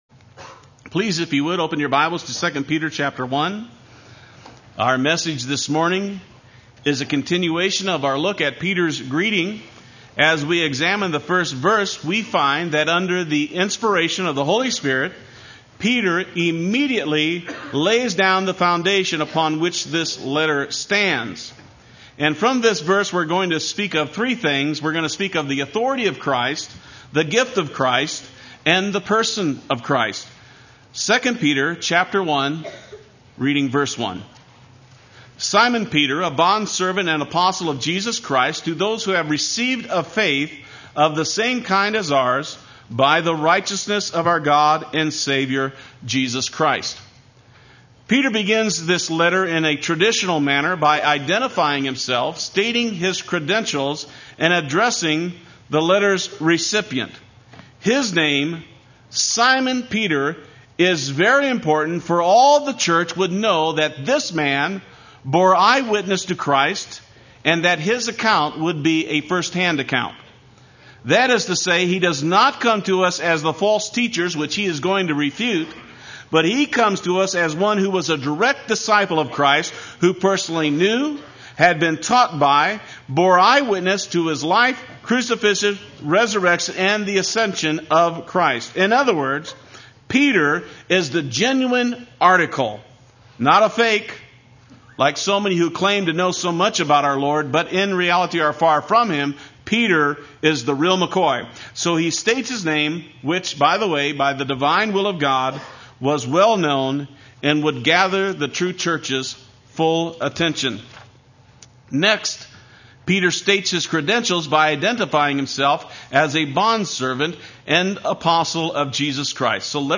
Play Sermon Get HCF Teaching Automatically.
Precious Faith Sunday Worship